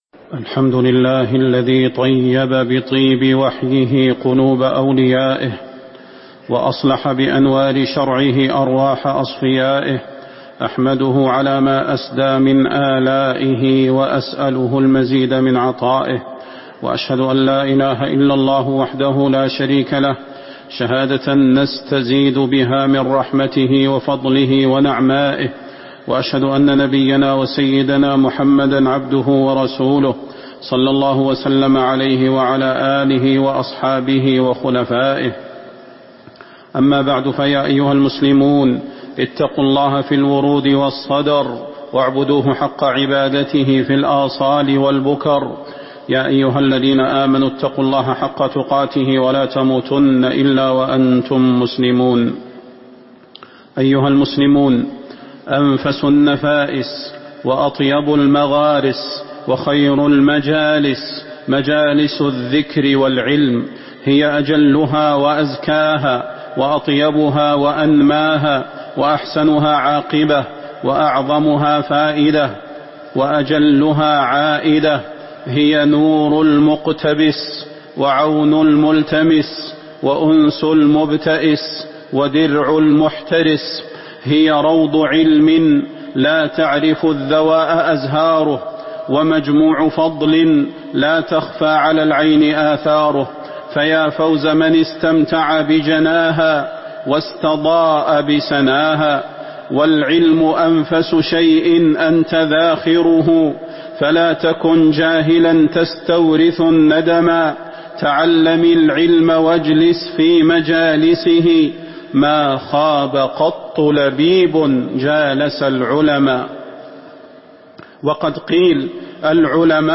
تاريخ النشر ٢٨ رجب ١٤٤٥ هـ المكان: المسجد النبوي الشيخ: فضيلة الشيخ د. صلاح بن محمد البدير فضيلة الشيخ د. صلاح بن محمد البدير فضائل مجالس الذكر